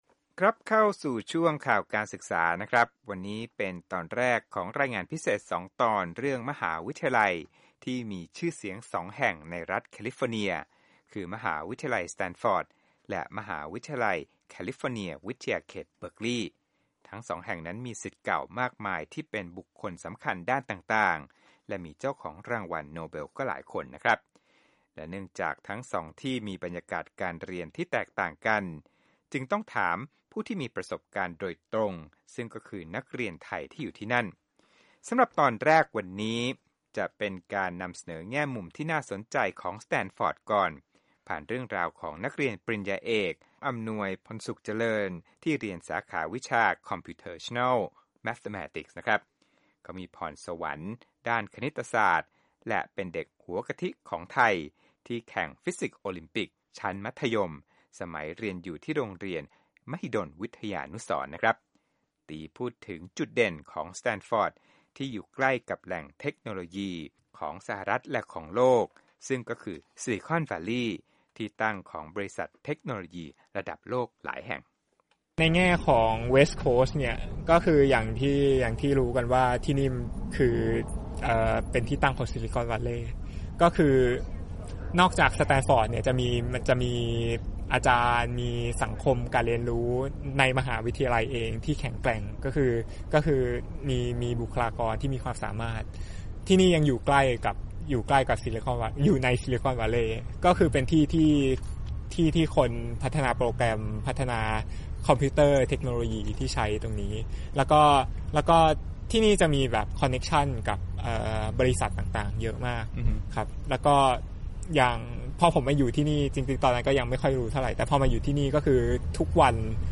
Interview Standford Student